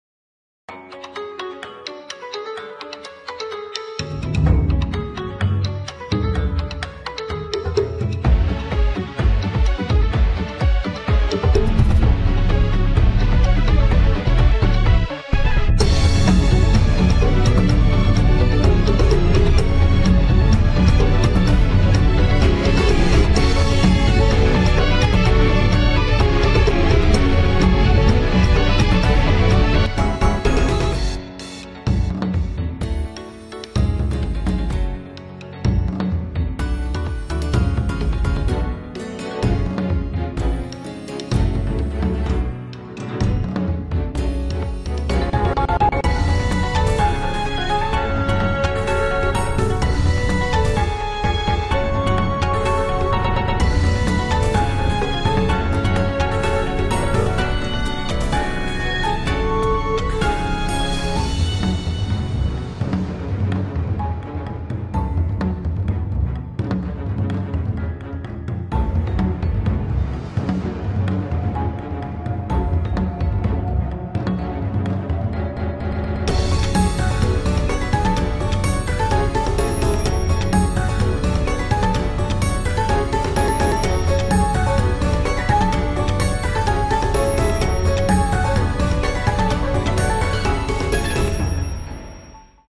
Aka EDM And Taiko Drum’s Sound Effects Free Download